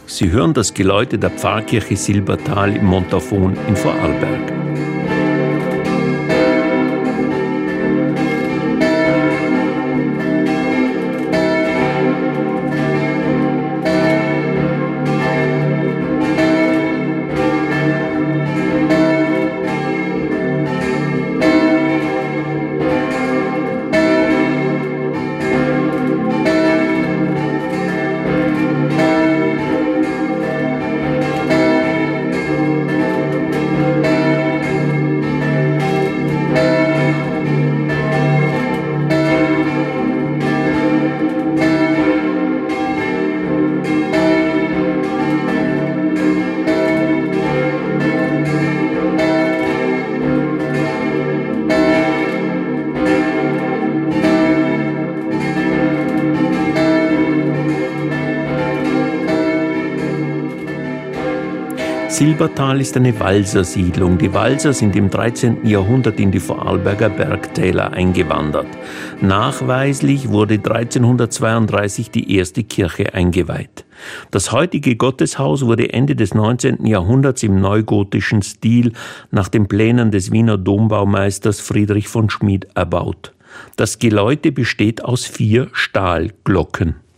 Silbertal-Mittagsglocken.mp3